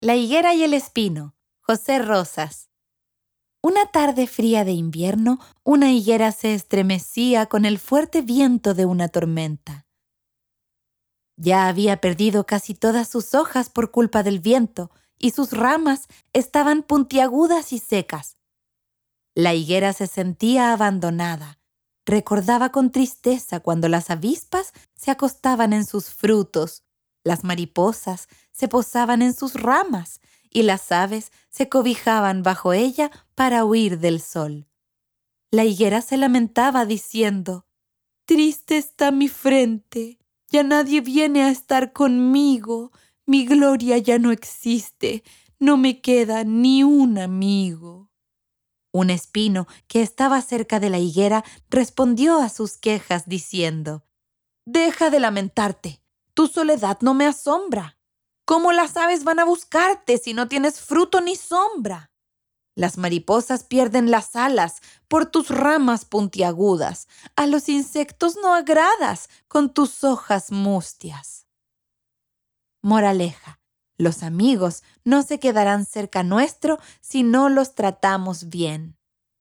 Audiocuento